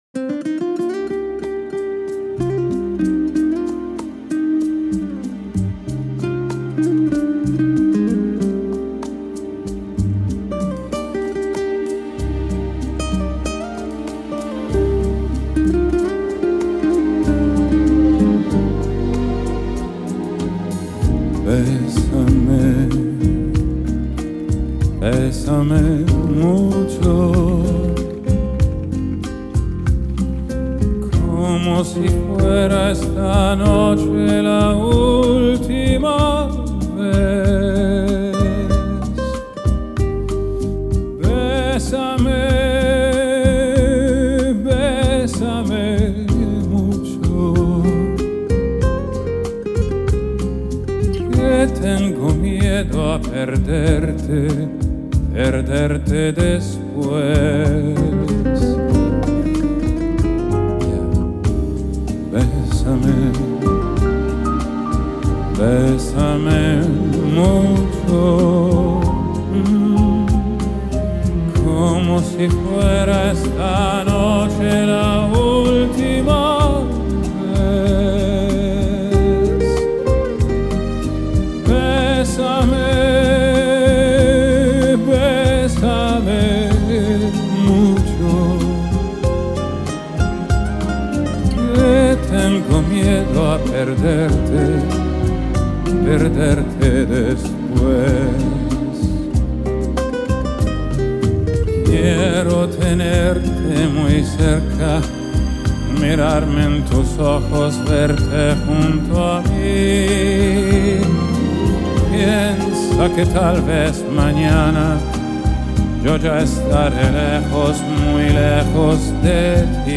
a 40 piece orchestra
Genre: Vocal, Classical, Crossover